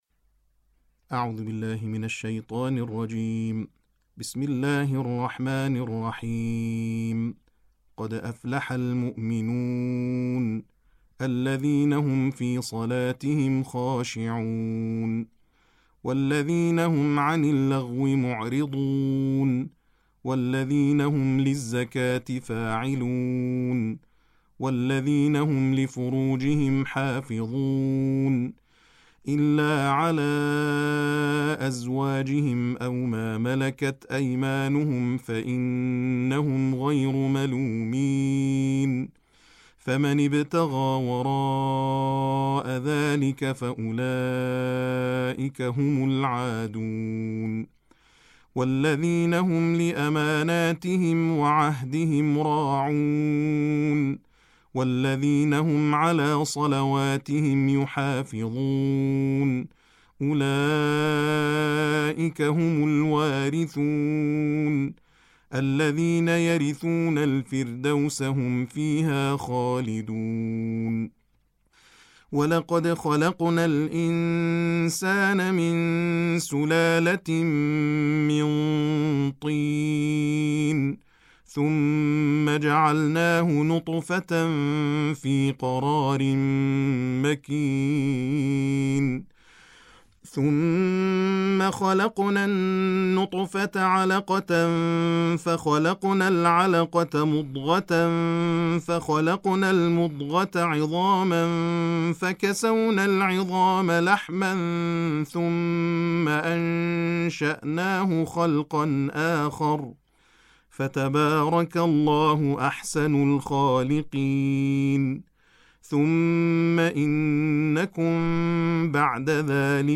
تلاوت ترتیل جزء هجدهم قرآن